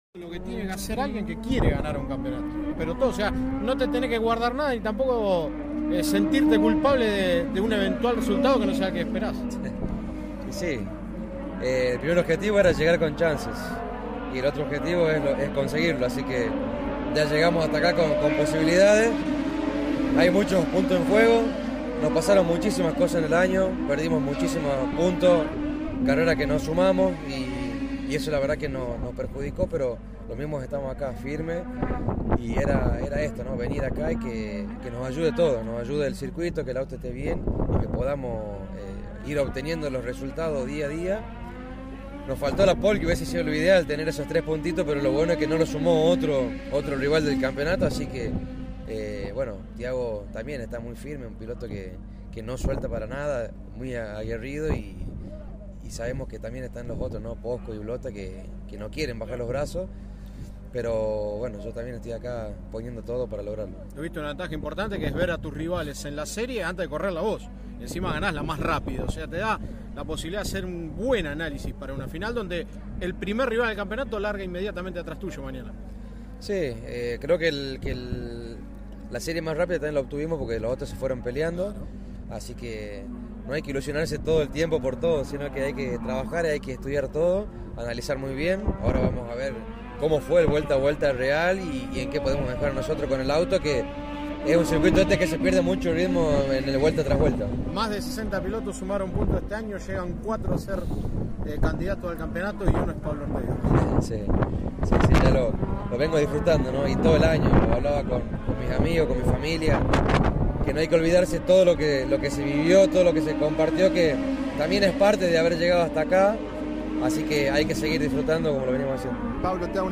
Al término de los parciales, los protagonistas dialogaron con CÓRDOBA COMPETICIÓN.